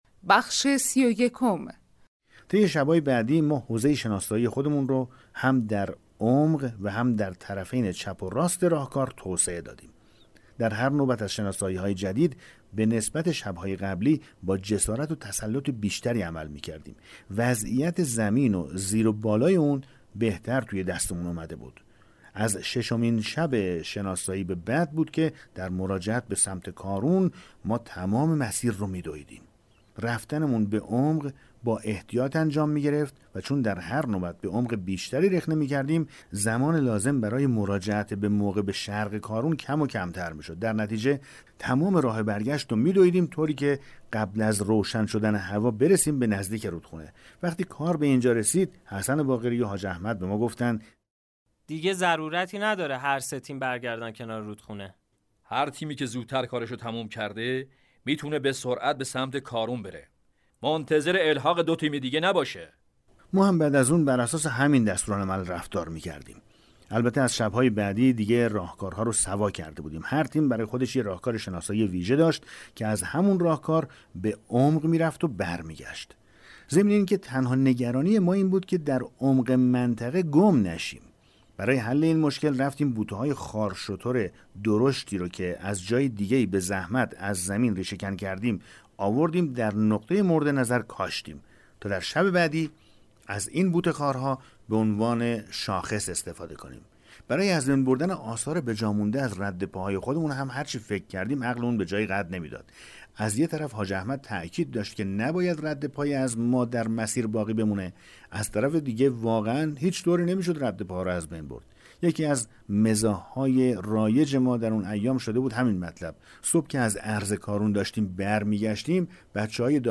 کتاب صوتی پیغام ماهی ها، سرگذشت جنگ‌های نامتقارن حاج حسین همدانی /قسمت 31